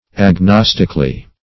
-- Ag*nos"tic*al*ly , adv.
agnostically.mp3